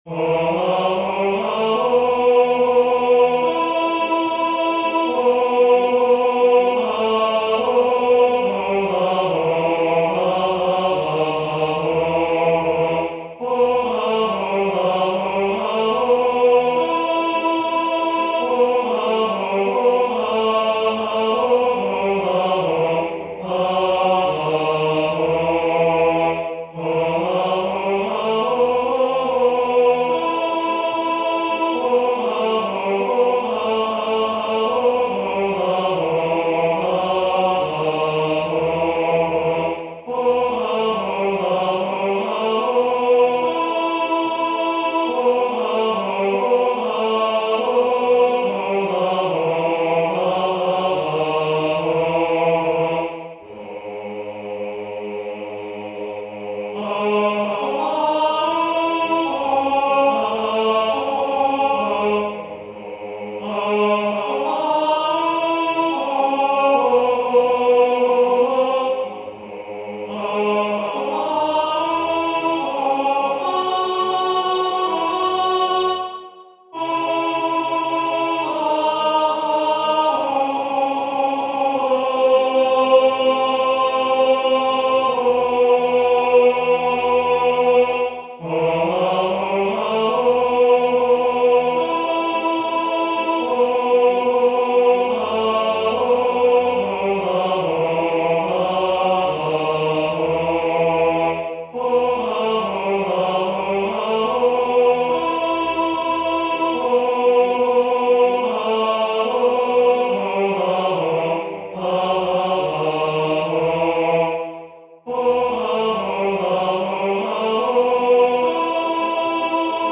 FF:VH_15b Collegium musicum - mužský sbor, FF:HV_15b Collegium musicum - mužský sbor